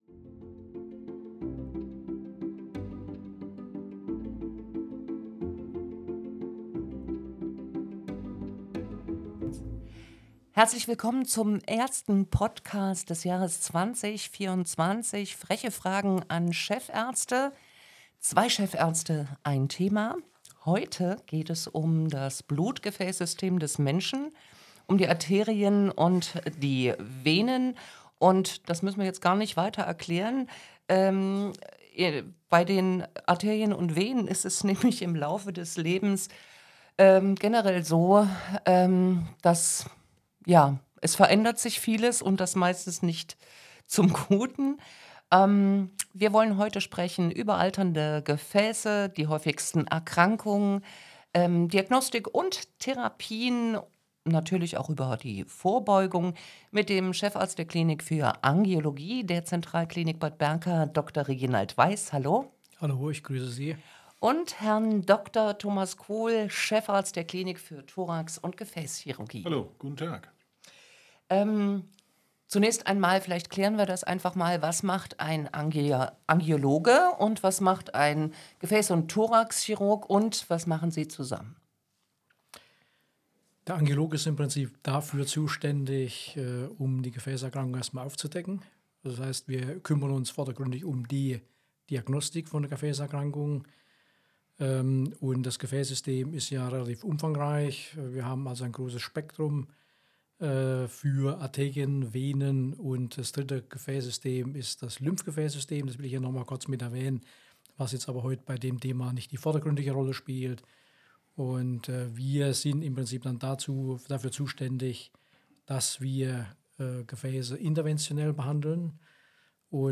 Zwei Chefärzte, ein Thema: Gefäßgesundheit